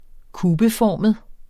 Udtale [ -ˌfɒˀməð ]